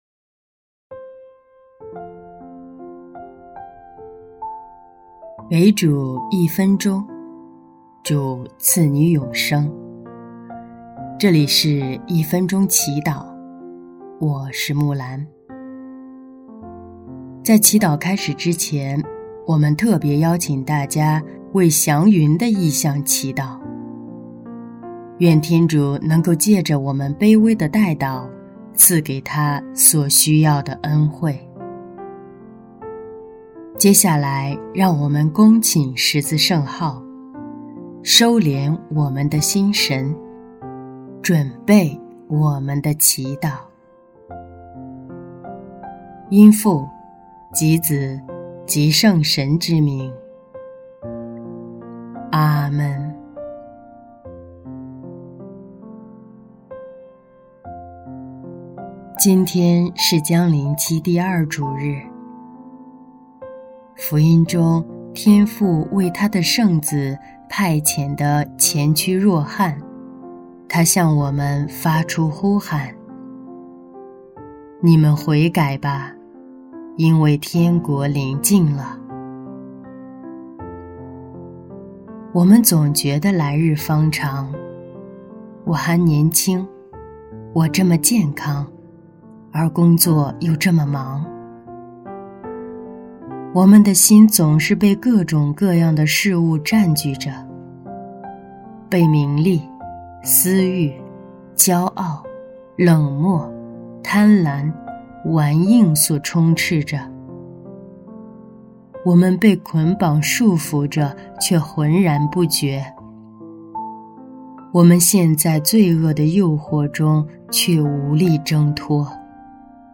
音乐：第一届华语圣歌大赛参赛歌曲《有主就有希望》